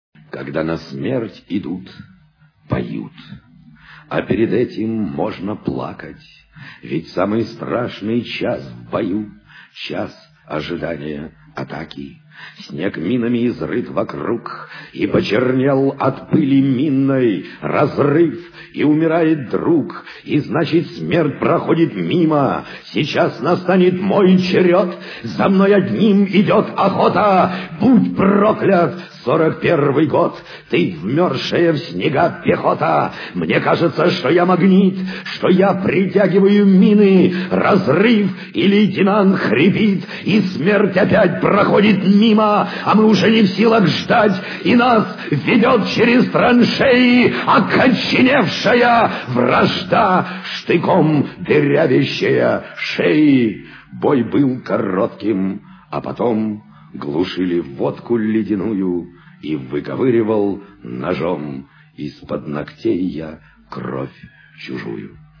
Это стихотворение звучало, конечно, и в спектакле «Павшие и живые», и существует даже соответствующая фонограмма с записью Высоцкого, но она настолько низкого качества, что лучше послушать другую запись, сделанную в 1976 году (скачать):